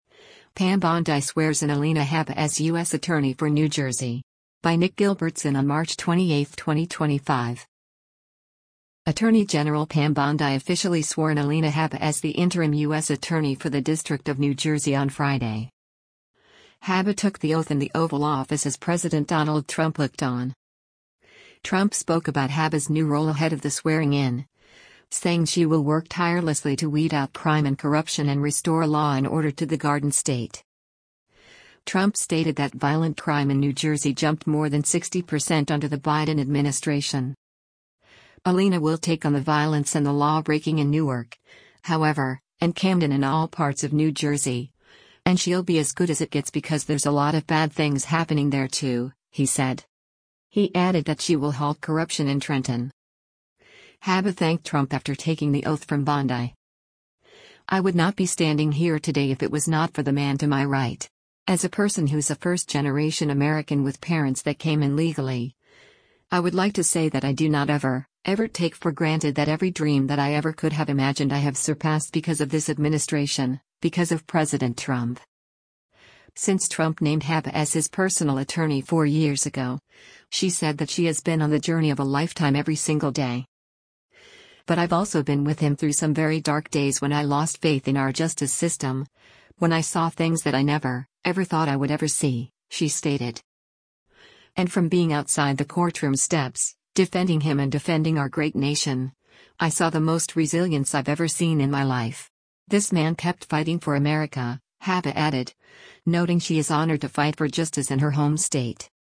Attorney General Pam Bondi officially swore in Alina Habba as the interim U.S. attorney for the District of New Jersey on Friday.
Habba took the oath in the Oval Office as President Donald Trump looked on.
Habba thanked Trump after taking the oath from Bondi: